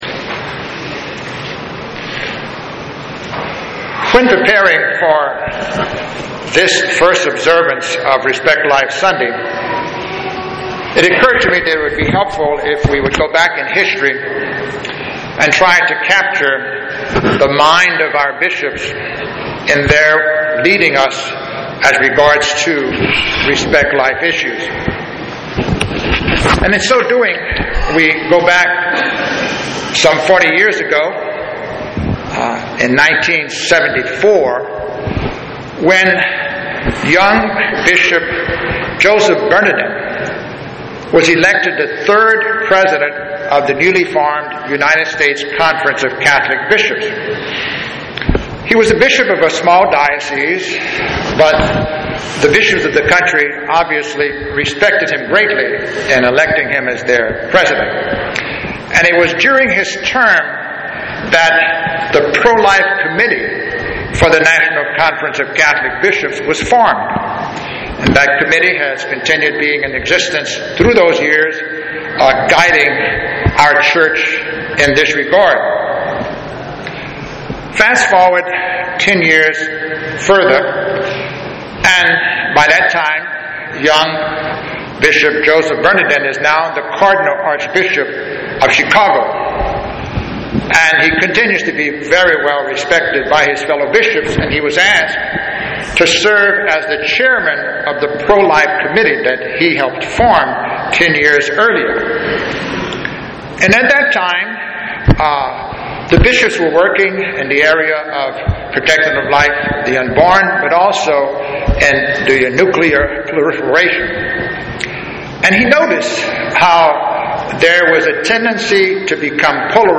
Documents CALIFORNIA APPROVES ASSISTED SUICIDE PURPOSE AND AREAS OF CONCERN (Committee) CONSISTENT ETHICS OF LIFE (Pastor's Homily) SEAMLESS GARMENT (Cardinal Bernadin) DISASTER FOR MEDICINE (Assisted Suicide)
CONSISTENT ETHICS OF LIFE Pastors Homily.mp3